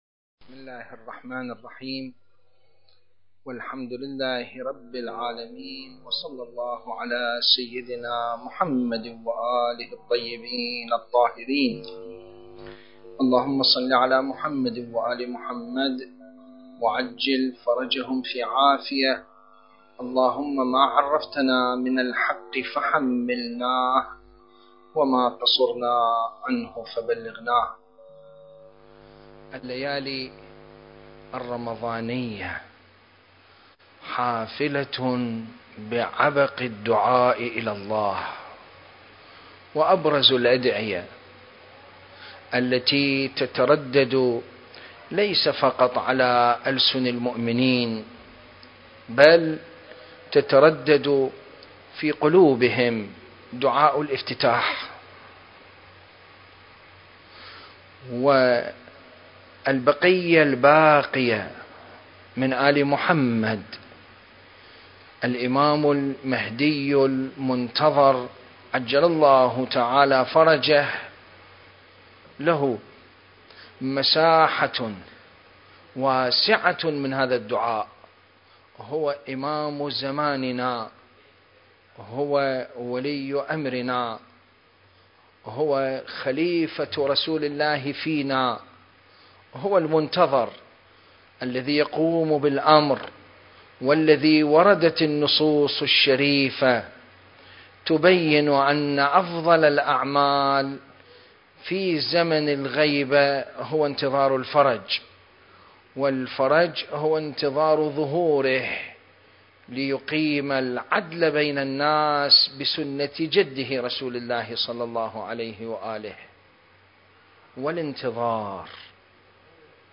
سلسلة: الإمام المهدي (عجّل الله فرجه) في دعاء الافتتاح (1) المكان: العتبة العلوية المقدسة التاريخ: 2021